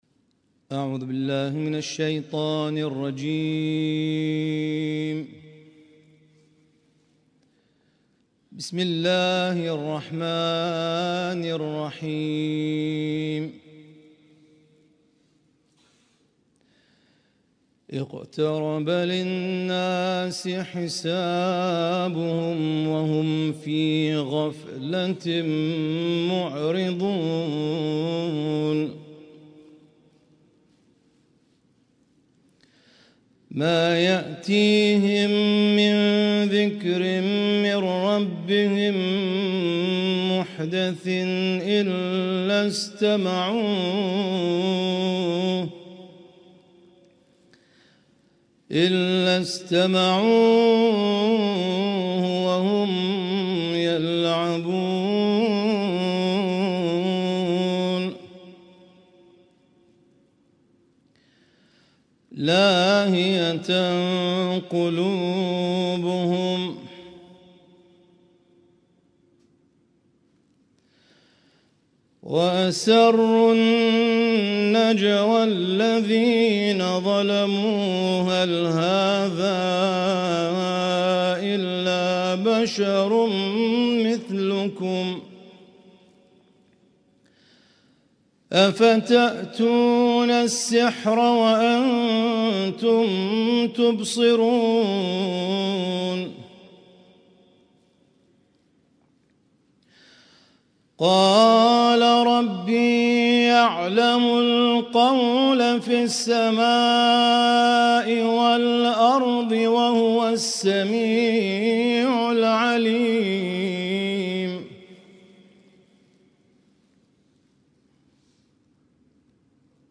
القارئ
اسم التصنيف: المـكتبة الصــوتيه >> القرآن الكريم >> القرآن الكريم - شهر رمضان 1446